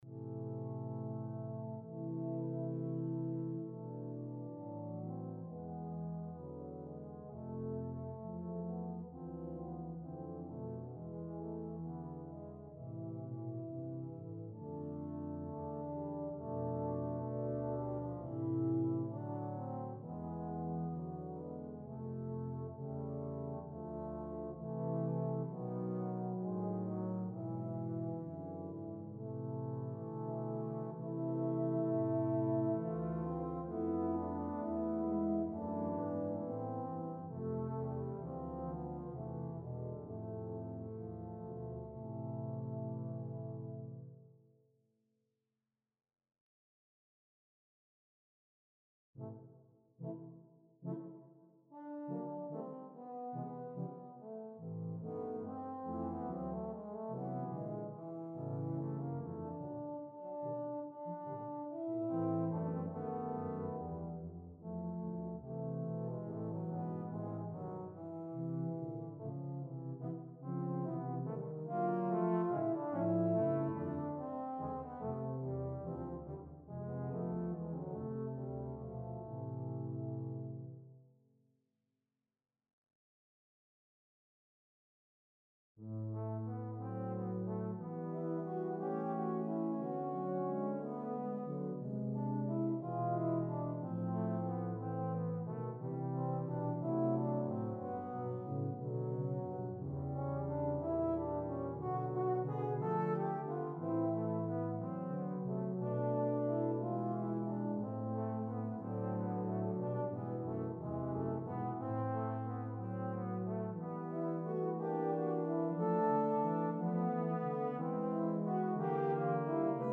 Besetzung: Tuba Quartet